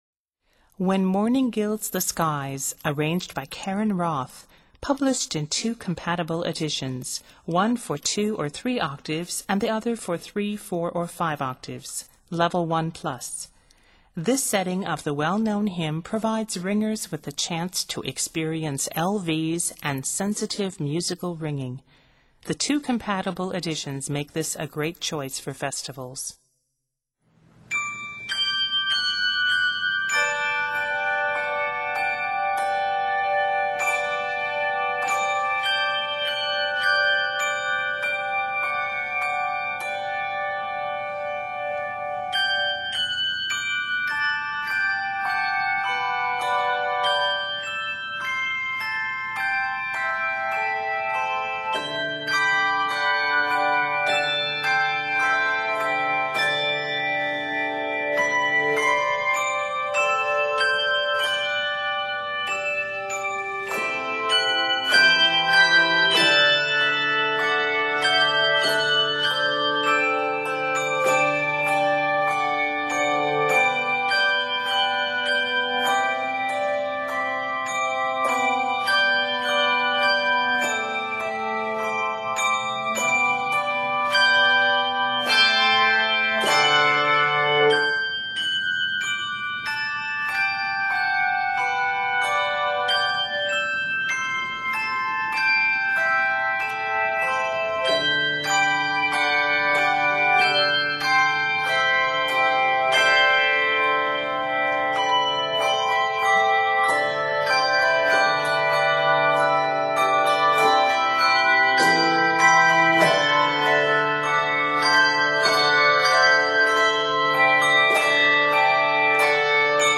in G Major